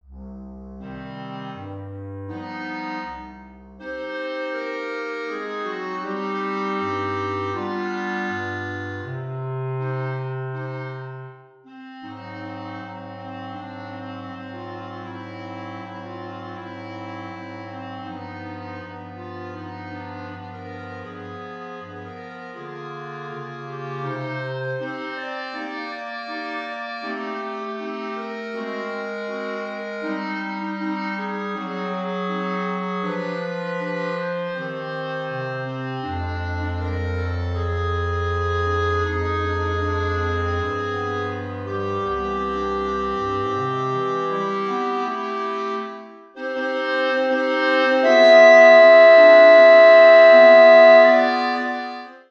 Klarinettenquartett/Saxophonquartett